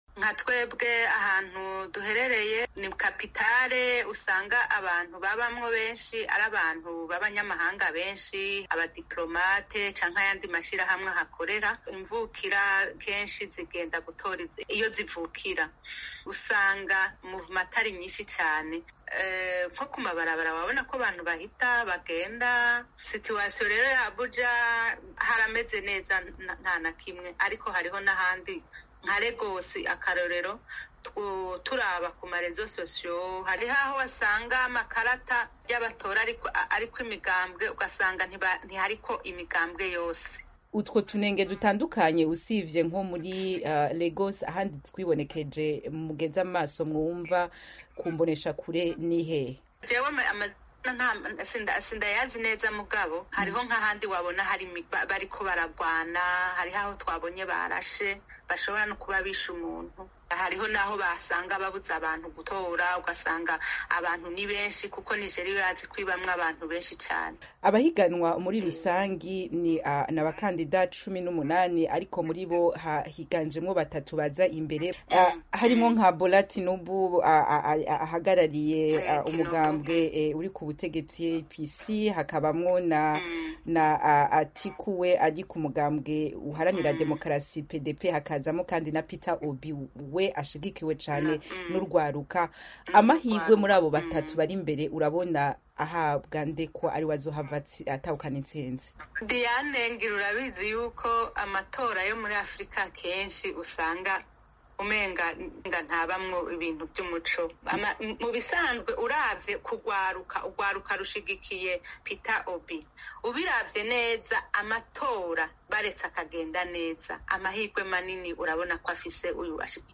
Mu kiganiro